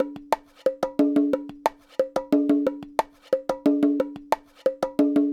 Bongo 16.wav